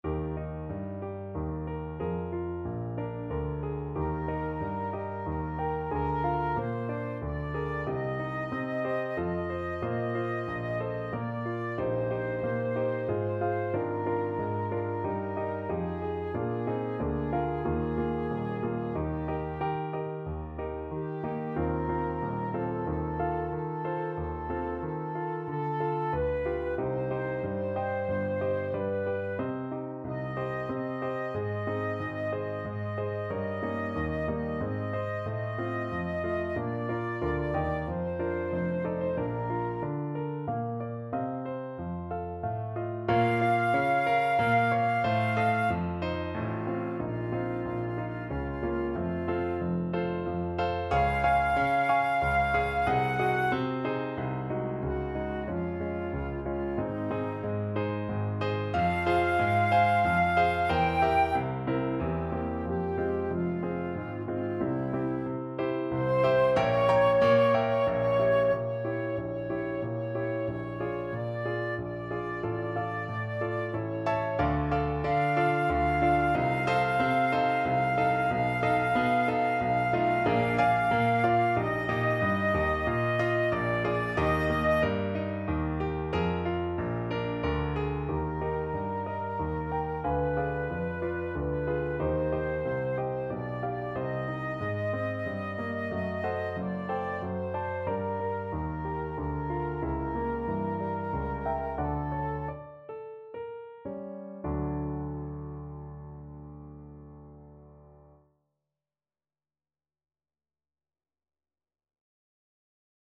Hostias Flute version
Flute
Eb major (Sounding Pitch) (View more Eb major Music for Flute )
3/4 (View more 3/4 Music)
~ = 92 Larghetto
Classical (View more Classical Flute Music)